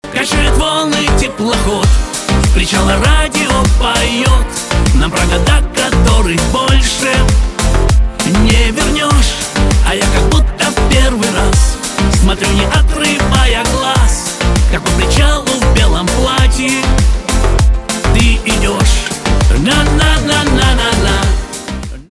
шансон
чувственные , битовые